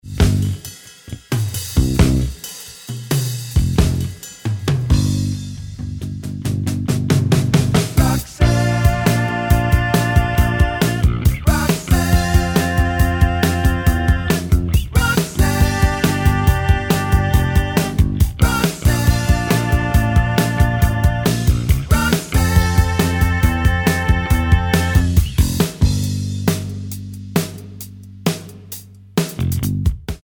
Tonart:Gm Ohne Guitar mit Chor